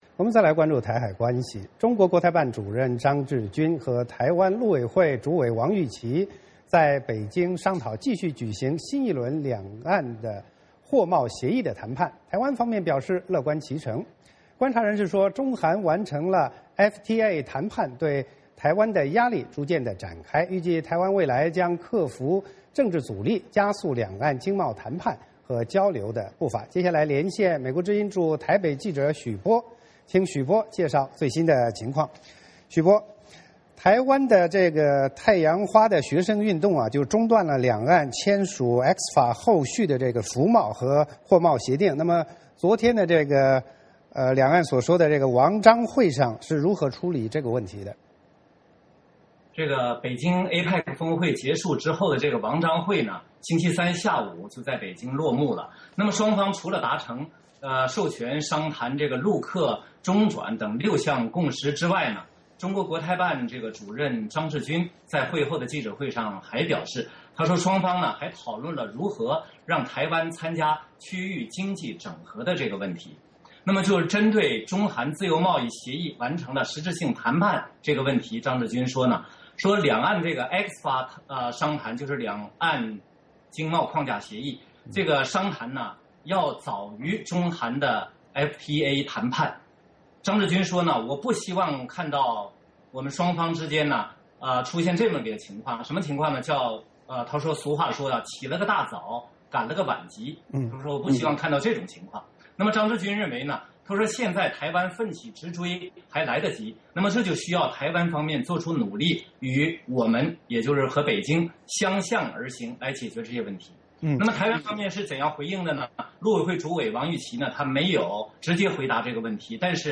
VOA连线：台海两岸继续商讨新一轮两岸货贸协议谈判